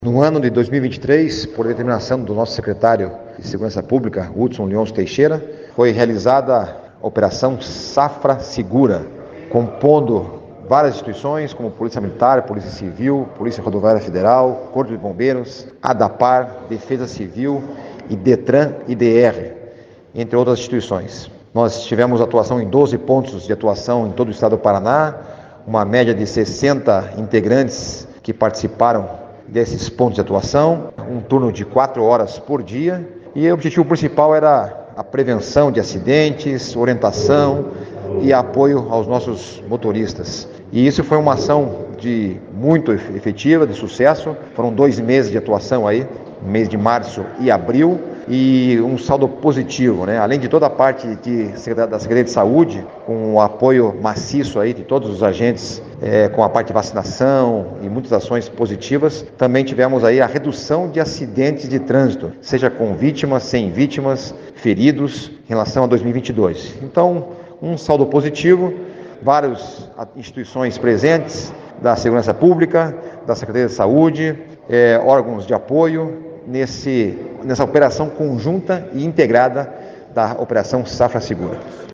Sonora do chefe da Coordenação de Operações Integradas de Segurança Pública, coronel Saulo de Tarso Sanson, sobre Operação Safra Segura